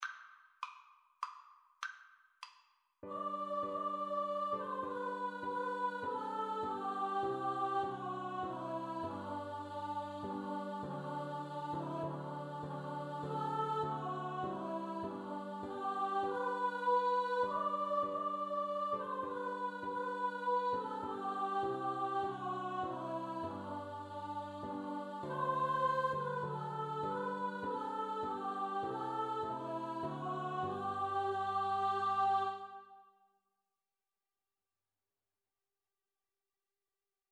• Choir (SATB)
3/4 (View more 3/4 Music)